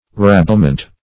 Search Result for " rabblement" : The Collaborative International Dictionary of English v.0.48: Rabblement \Rab"ble*ment\ (r[a^]b"b'l*ment), n. A tumultuous crowd of low people; a rabble.